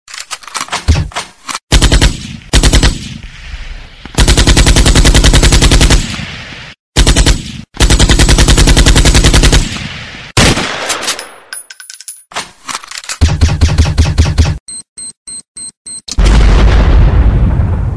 ริงโทนเสียงปืน
เสียงเรียกเข้ายิงปืน Remix เสียงปืนเล็กยาว ตำรวจ
Nhac-chuong-tieng-sung-www_tiengdong_com.mp3